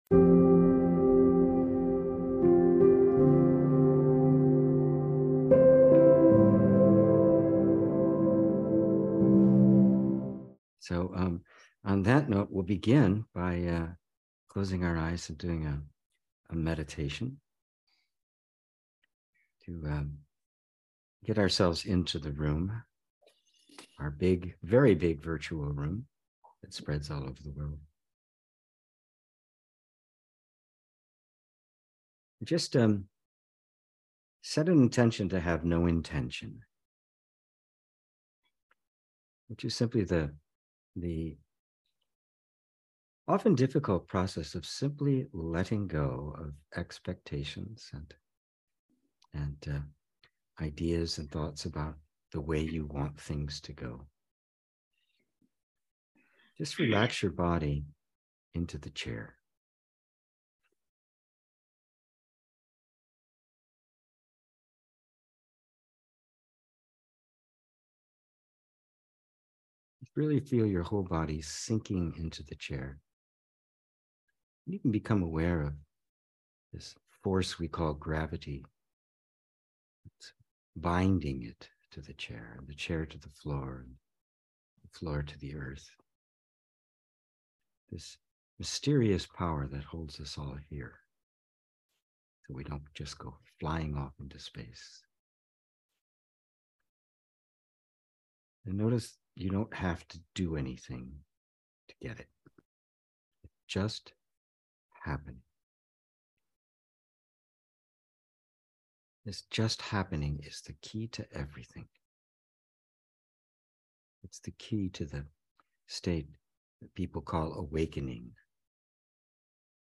The first recording from a four-part online workshop taking a journey through Freedom from the Known.